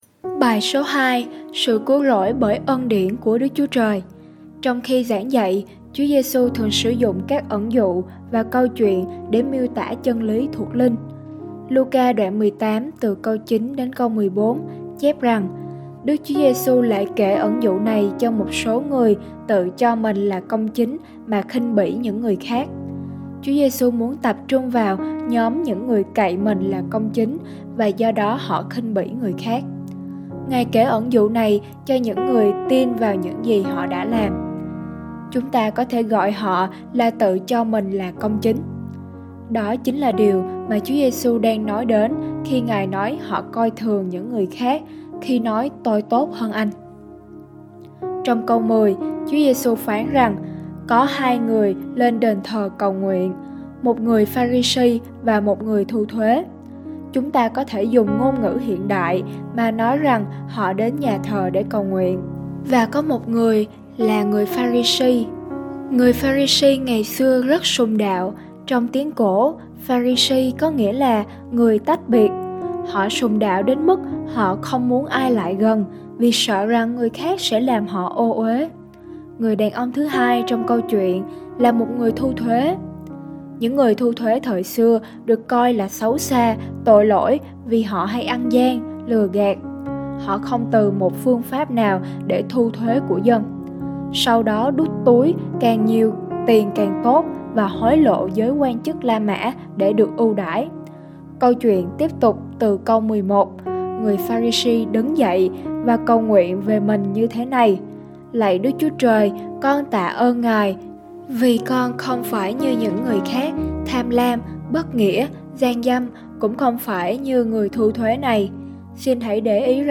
BÀI HỌC